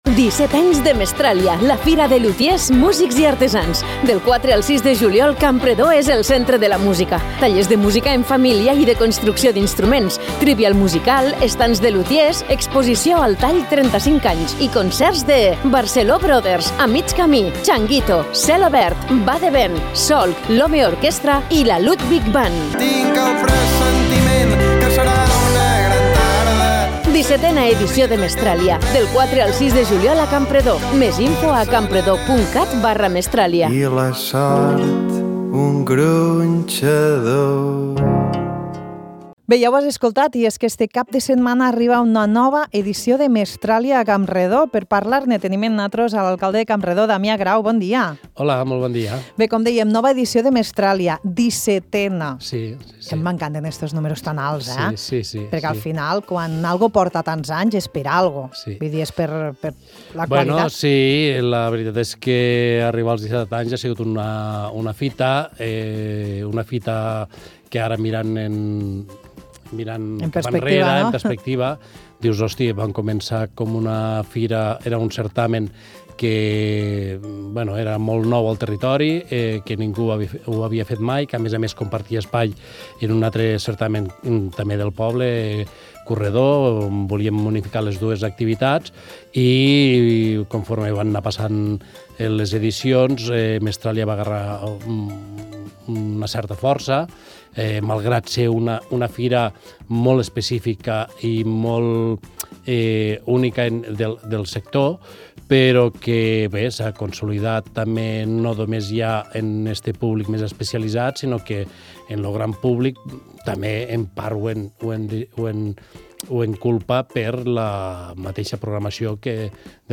Amb Damià Grau, alcalde de Campredó, parlem sobre els detalls de la 17a edició de Mestràlia, fira de lutiers i constructors artesans. Com a novetat, cal destacar que enguany la fira canvia d’ubicació, passant a l’Espai Cultural La C, per oferir més espai i millorar l’organització d’activitats i estands.